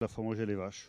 Elle provient de Sallertaine.
Locution ( parler, expression, langue,... )